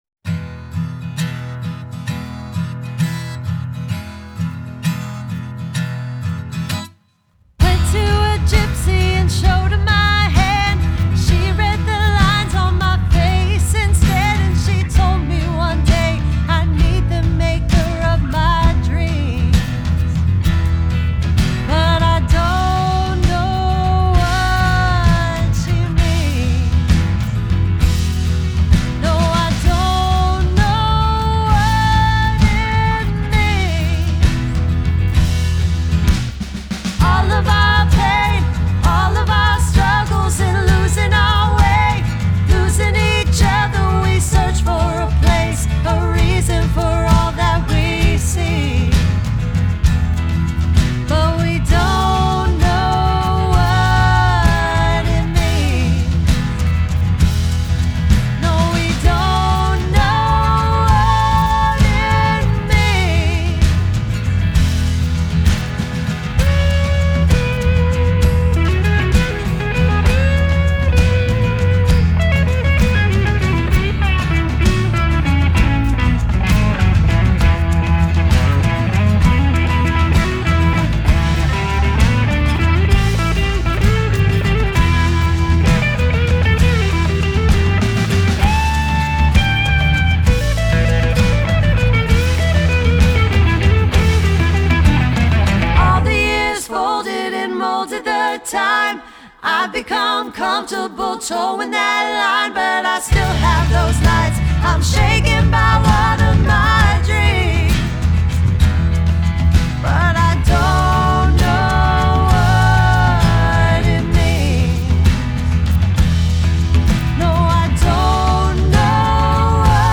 Genre: Folk Pop, Jazzy Folk